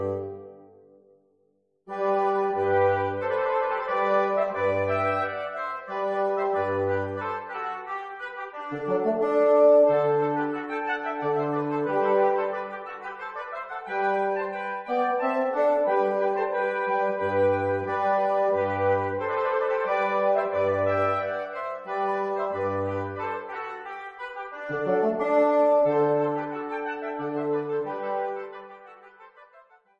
2 oboes, 2 horns, bassoon